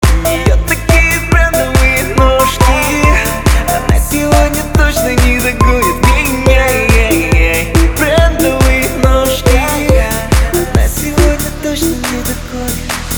поп
мужской вокал
Жанр: Хип-хоп/рэп